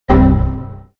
This is a meme voice effect, often used in funny, triumphant, or funny moments often seen in CapCut. meme sound voice ok correct answer